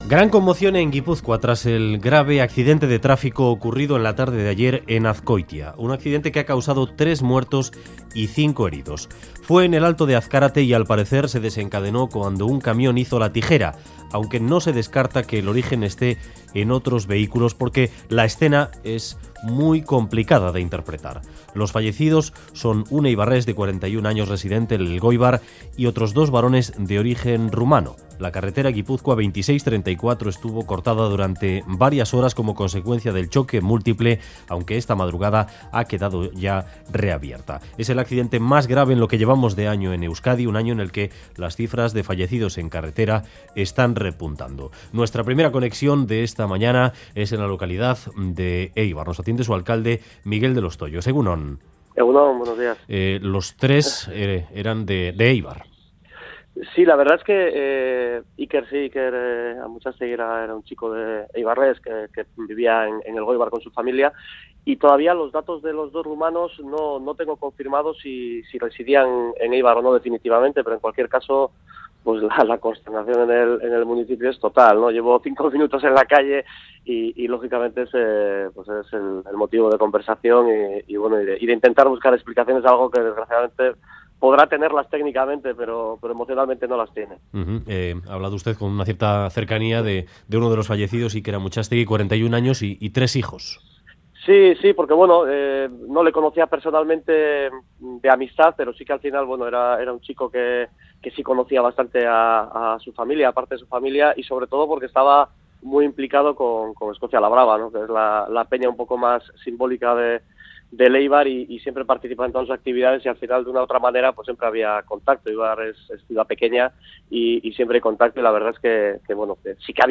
Audio: El alcalde de Eibar lamenta el accidente donde 3 personas del municipio fallecieron tras hacer un camión la tijera en el alto de Azkarate.